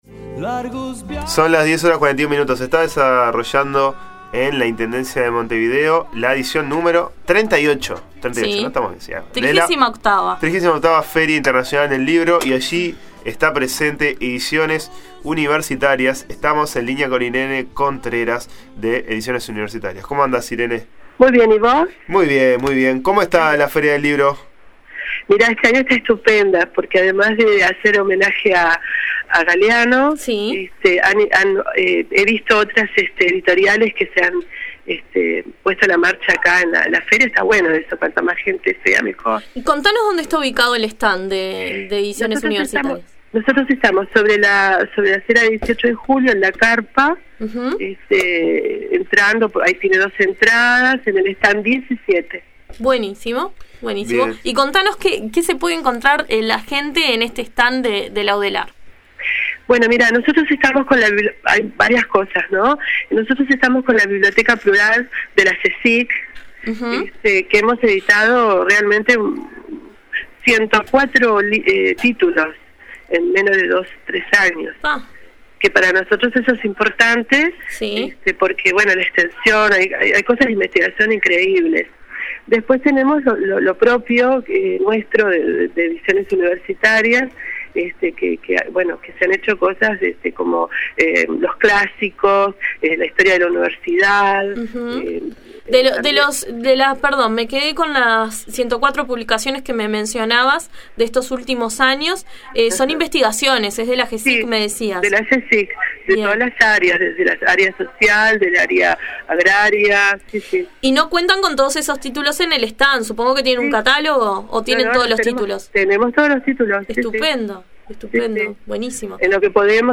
Con motivo de la edición número 38.a Feria Internacional del Libro La Nueva Mañana realizó entrevistas para cubrir uno de los eventos editoriales más importantes de la ciudad de Montevideo.